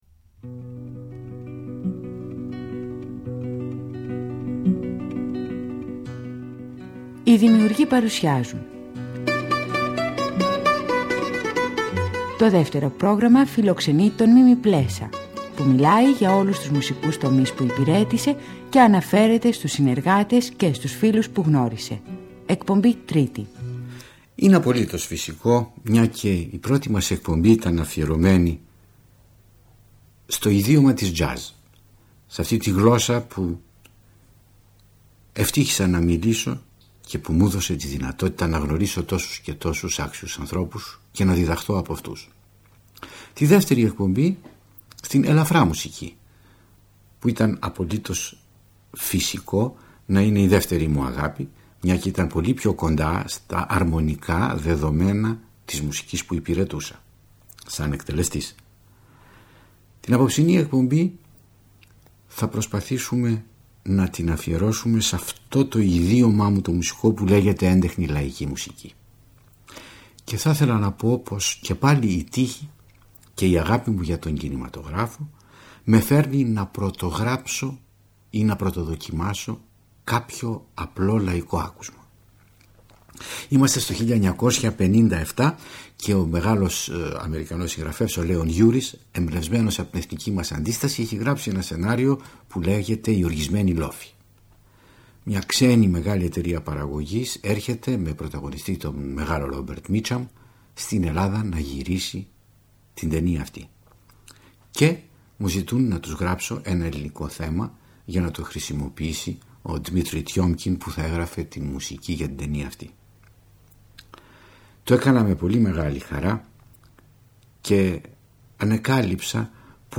Στις εκπομπές αυτές, ο μεγάλος συνθέτης αυτοβιογραφείται, χωρίζοντας την έως τότε πορεία του στη μουσική, σε είδη και περιόδους, διανθίζοντας τις αφηγήσεις του με γνωστά τραγούδια, αλλά και με σπάνια ηχητικά ντοκουμέντα.